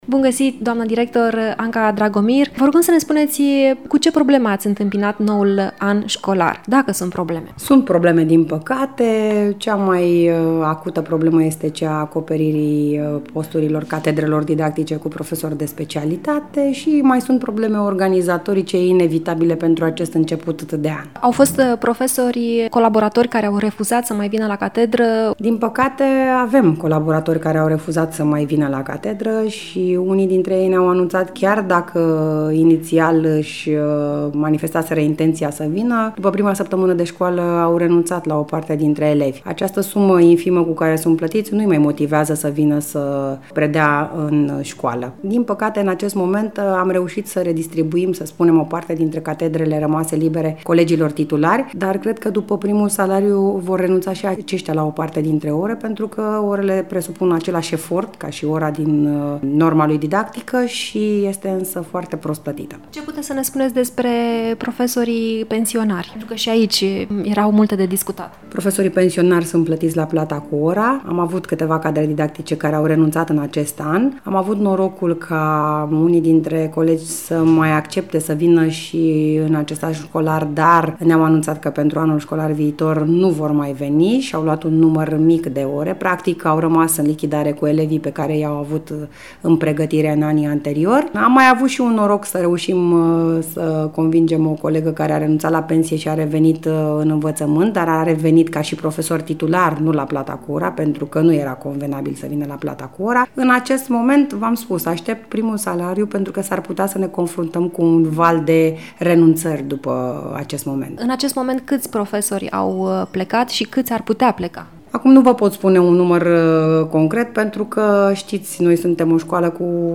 Urmează un interviu